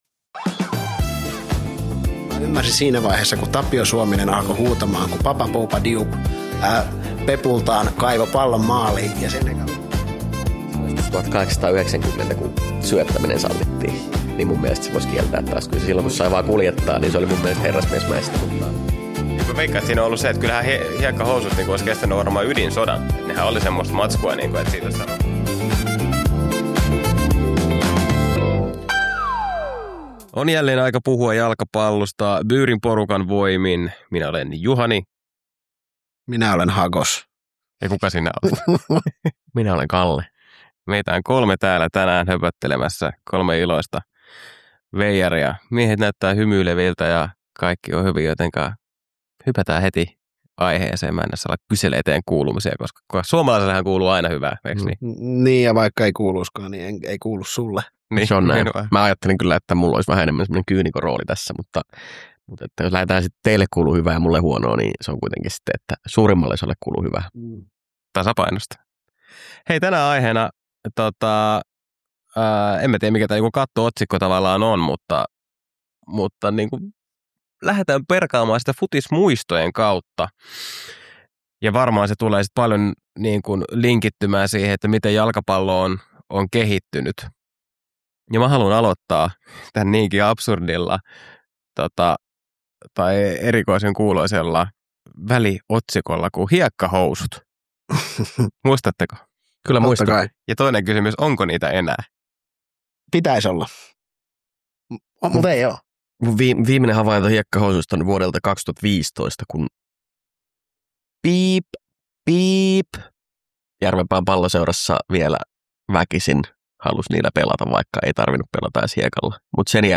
Byyrin uuden Pilkun jälkeen -podin avausjaksossa perataan jalkapallon lajikehitystä panelistien omien futismuistojen kautta. Mitä tapahtui hiekkahousuille ja jaksaako nuoriso enää katsoa kokonaista 90-minuuttista?